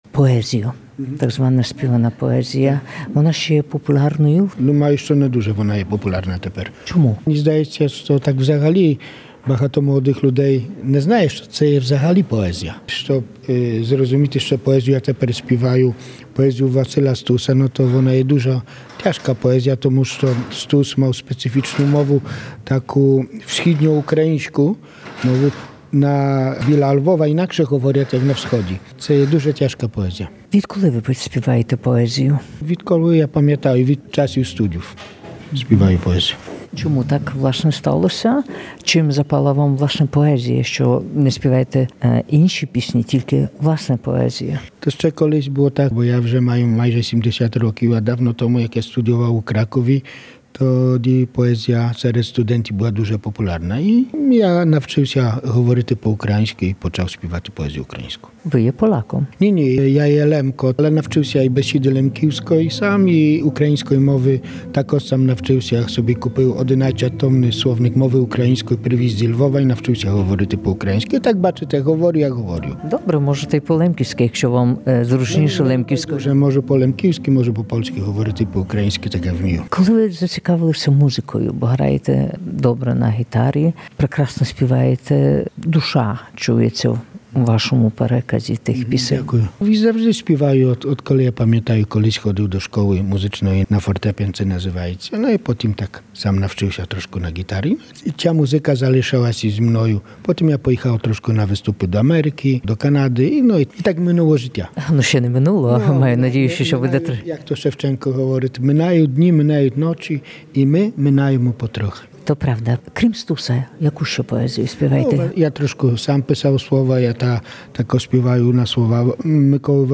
Співає пісні українських поетів.
виступив в Народному домі на поетично-музичному вечорі